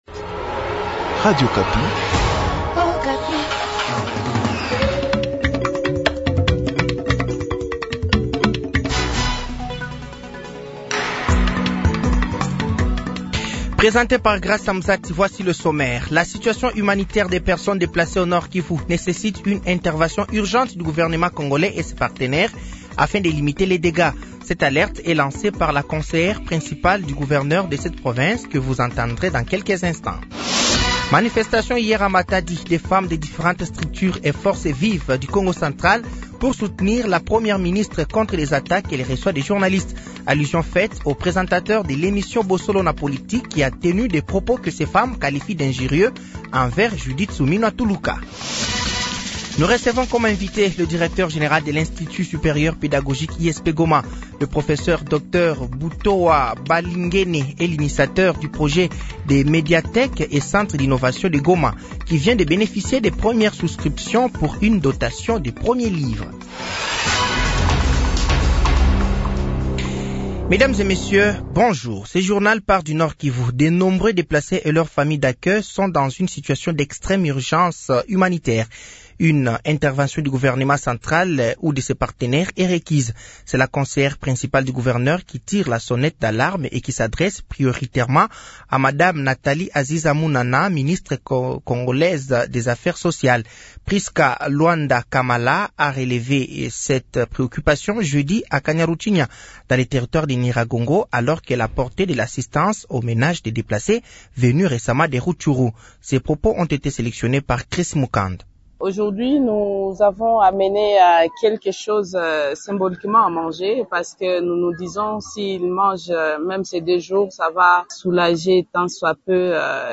Journal français de 12h de ce samedi 02 novembre 2024